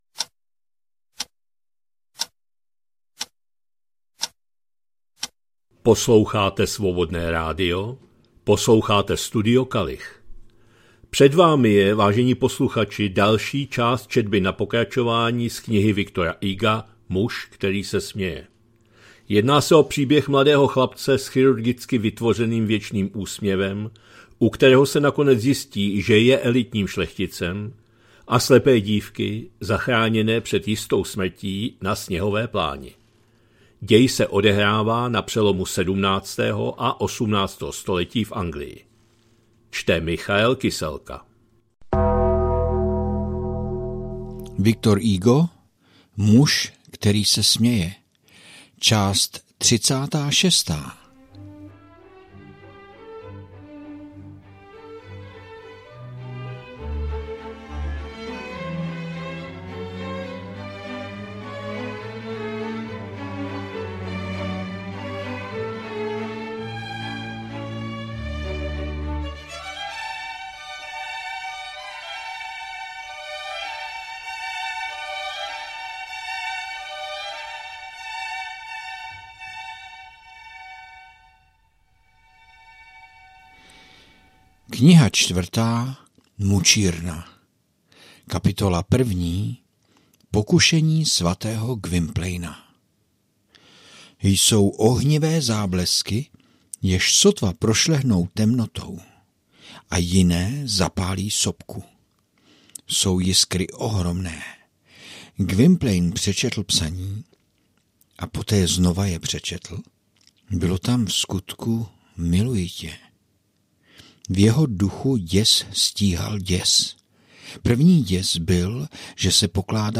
2025-11-20 – Studio Kalich – Muž který se směje, V. Hugo, část 36., četba na pokračování